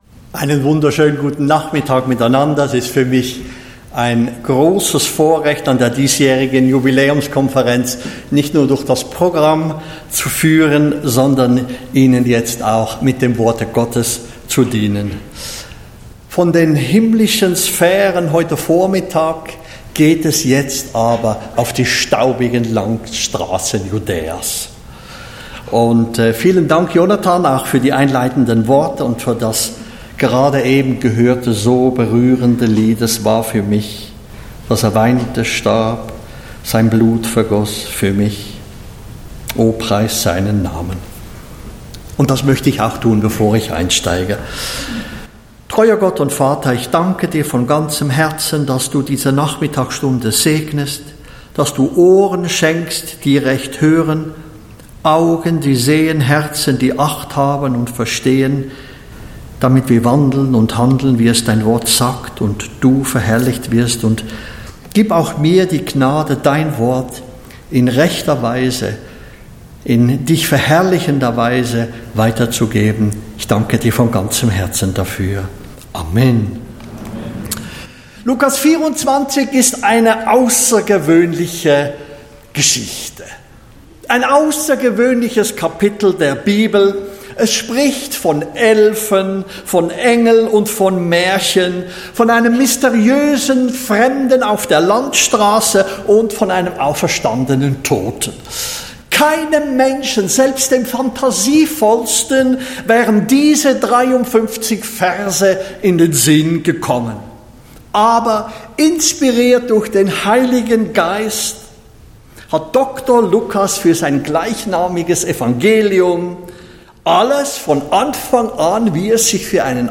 Botschaft Zionshalle https